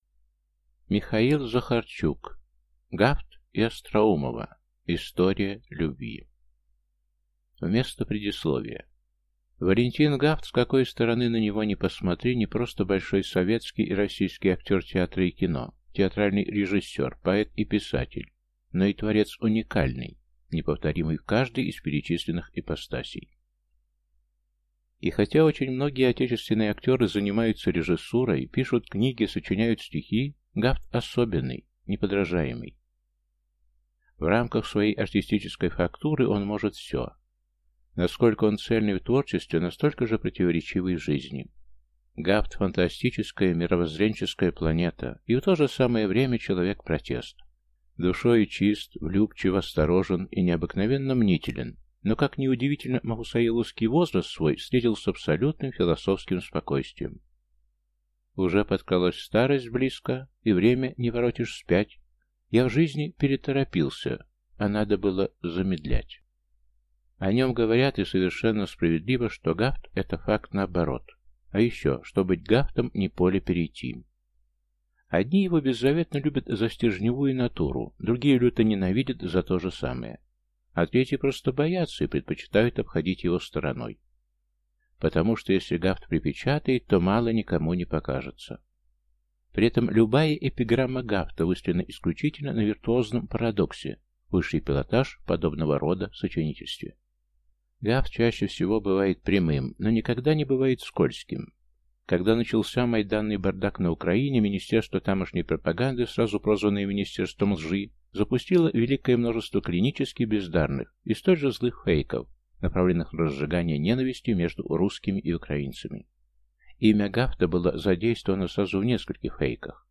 Аудиокнига Гафт и Остроумова. История любви | Библиотека аудиокниг